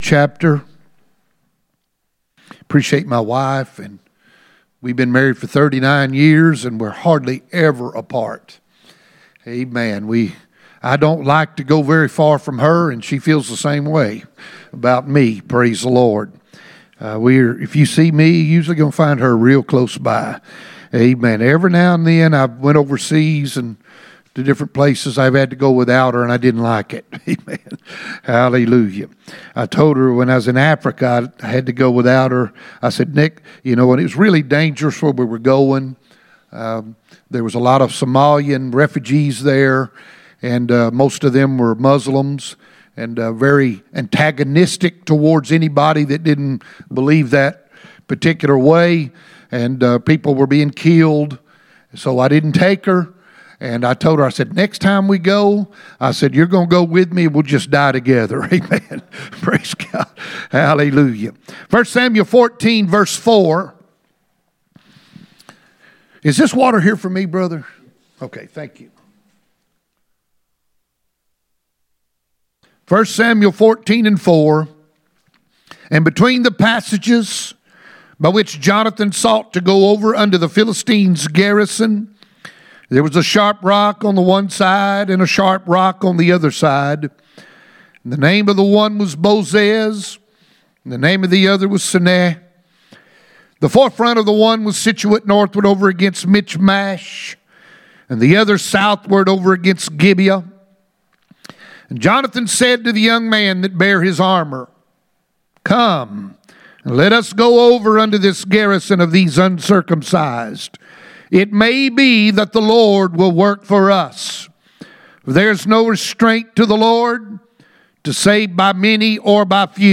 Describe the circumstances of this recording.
None Passage: 1 Samuel 14:4-14 Service Type: Sunday Morning %todo_render% « Unity of the Church